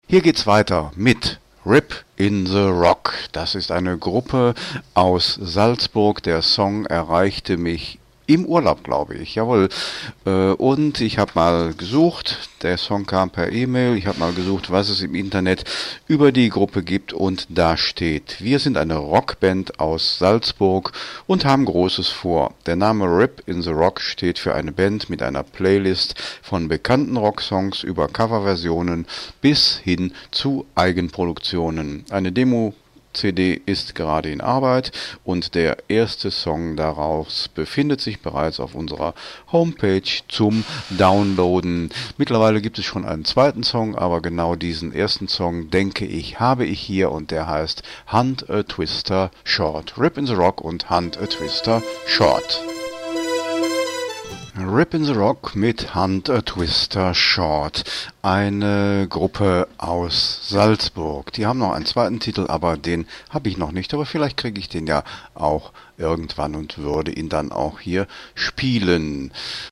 Mitschnitt-Radioauftritt